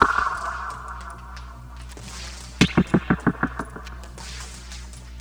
Back Alley Cat (Pecussion FX 03).wav